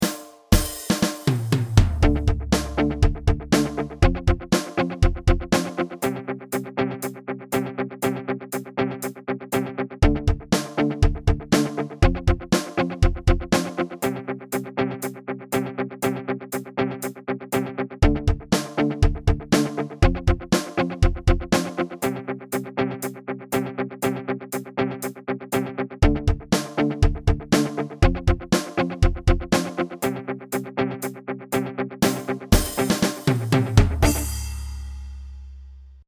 A classic bucket rock pattern
• An audio backing track to play along with in class